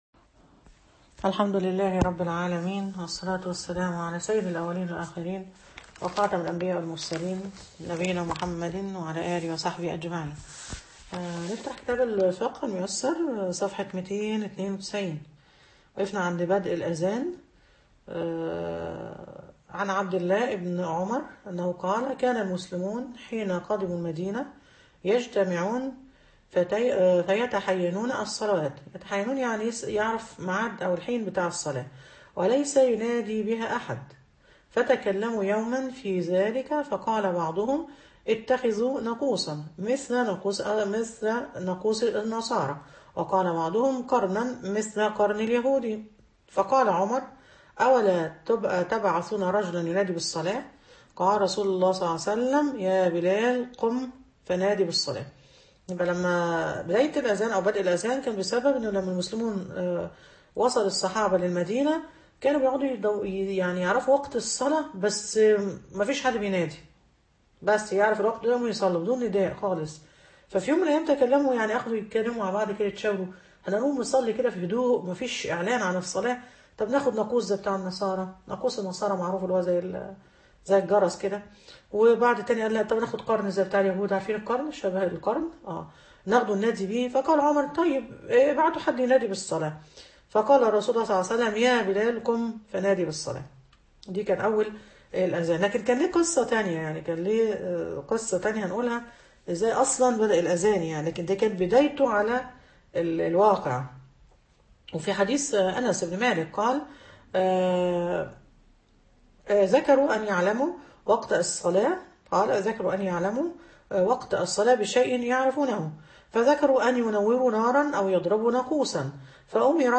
فقة الصلاة_المحاضرة الثالثة